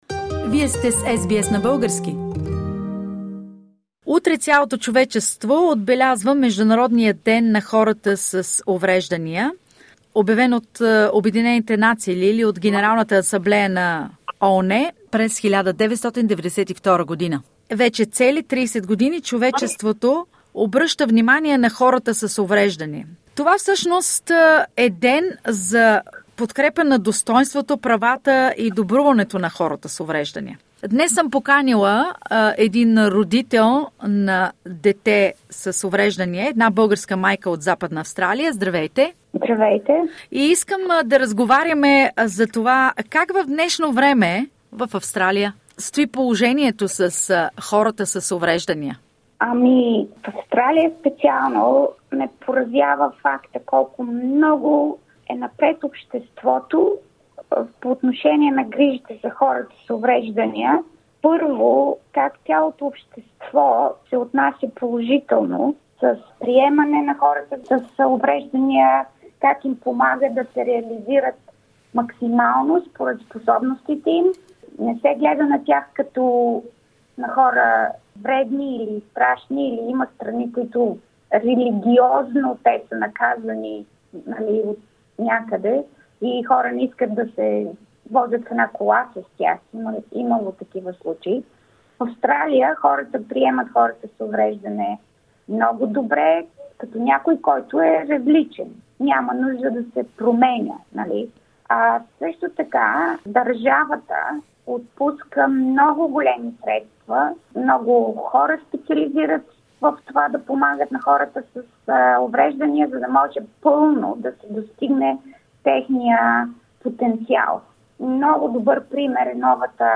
Как в Австралия се подпомагат хората с увреждания - разказва майка от Западна Австралия